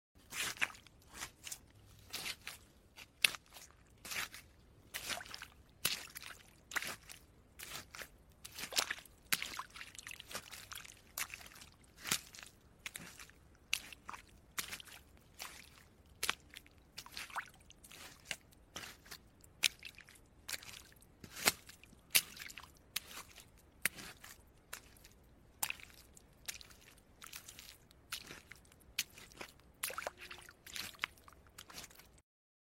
Tiếng Bước Chân đi trong Bùn nhão, Đầm lầy, dưới Nước…
Tiếng Bước Chân đi trên Bùn lầy, Vũng nước… Tiếng Bước chân đi trên Đất Bùn, Ẩm ướt…
Thể loại: Tiếng động
Âm thanh ẩm ướt, dính nhớp, nặng nề, kèm tiếng tóe nước, lụp bụp khi giày cọ vào bùn.
tieng-buoc-chan-di-trong-bun-nhao-dam-lay-duoi-nuoc-www_tiengdong_com.mp3